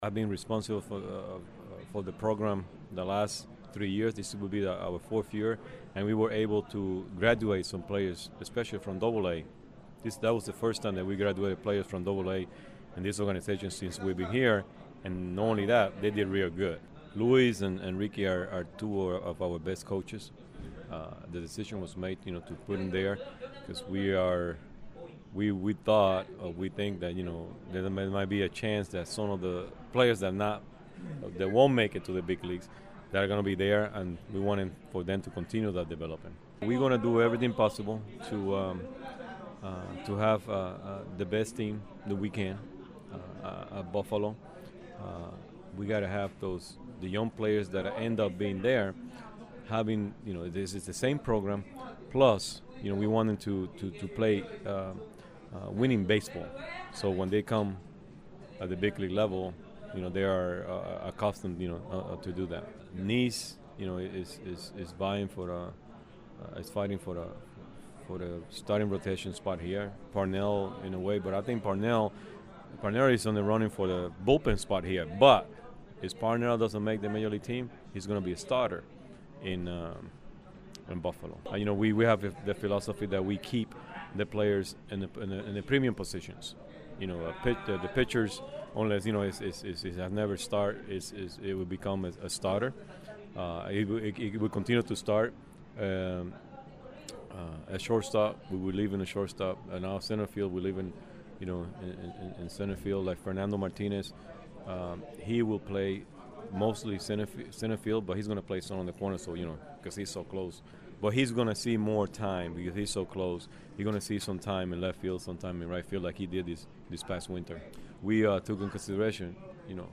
I took the liberty to break some of the conversations down and give you the meat and potatoes:
VP/AGM – Tony Bernazard CLICK HERE!